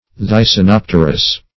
Thysanopterous \Thy`sa*nop"ter*ous\, a.
thysanopterous.mp3